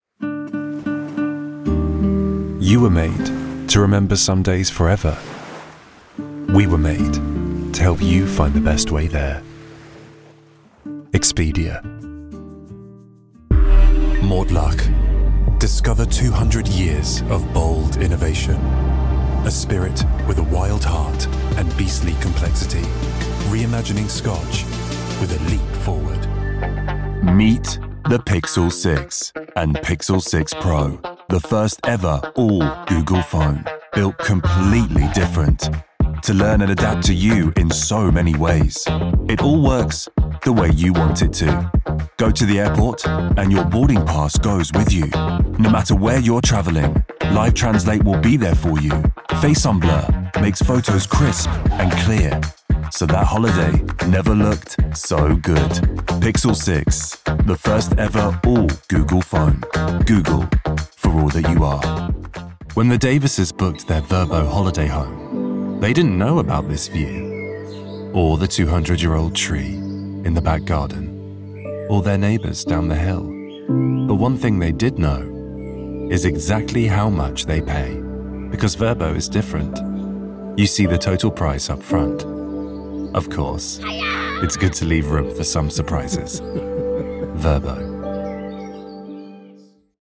Demonstração Comercial
BRITISH VOICE OVER ARTIST IN LONDON.
Nuemann U87 2024 / Nuemann TLM 193. Shure SM7B.
Sound proofed booth (Session Booth)